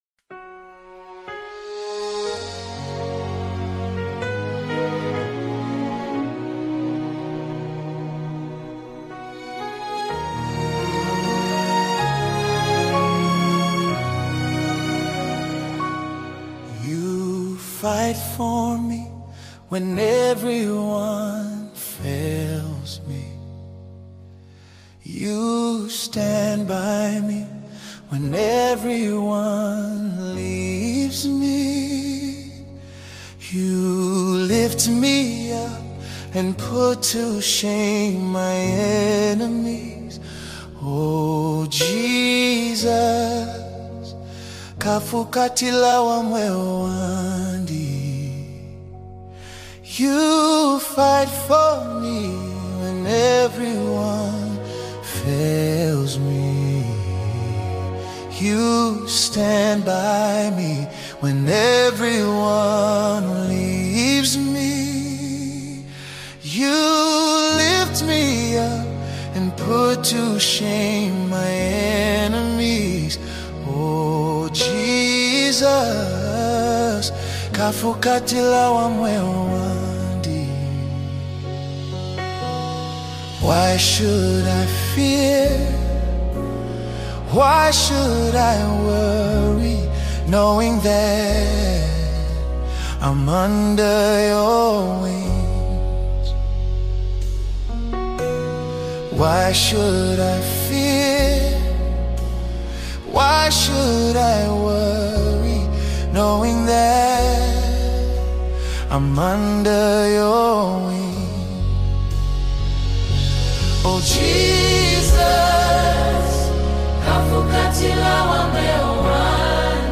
🎼 GENRE: ZAMBIAN GOSPEL MUSIC